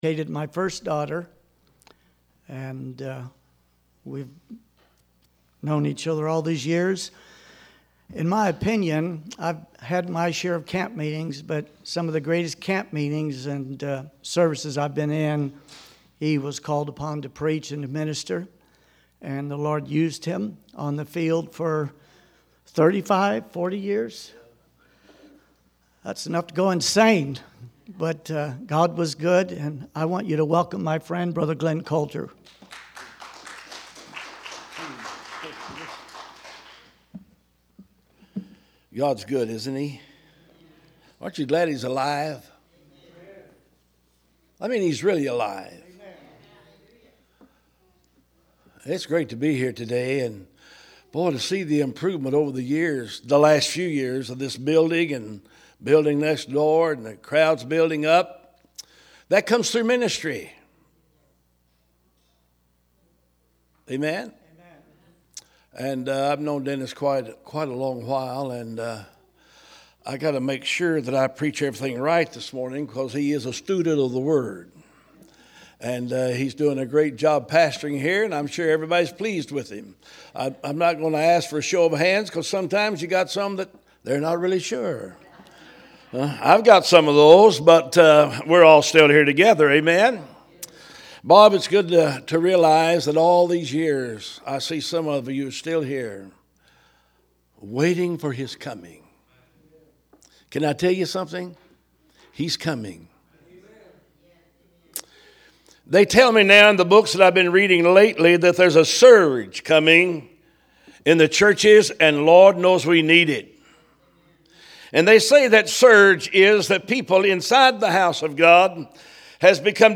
Special Sermons